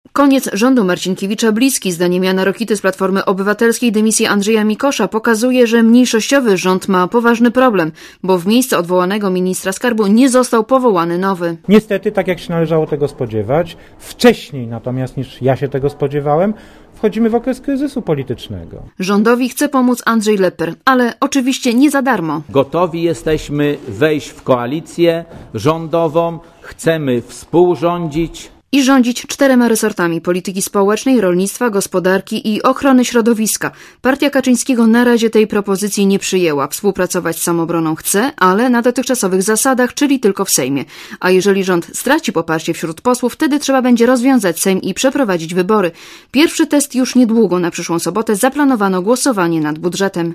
Relacja reportera Radia ZET Rokita skrytykował Marcinkiewicza w kontekście dymisji ministra skarbu Andrzeja Mikosza.